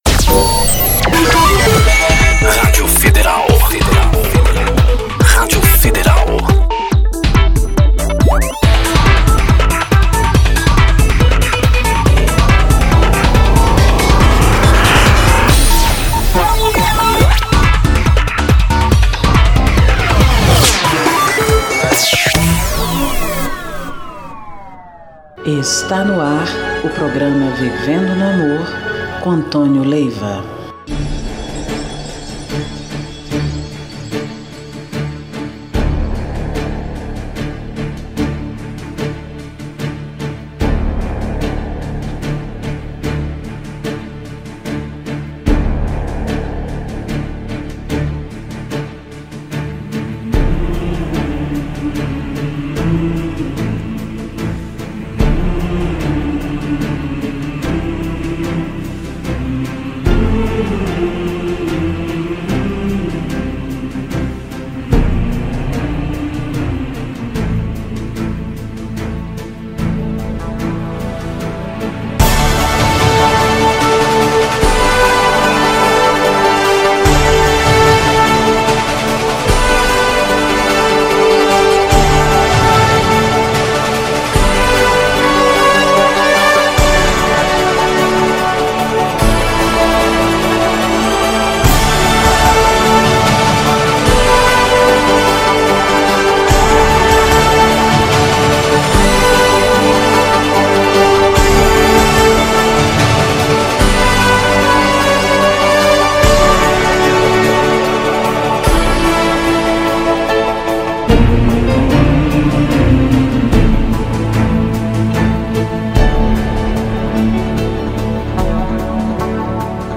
MENSAGENS E MÚSICAS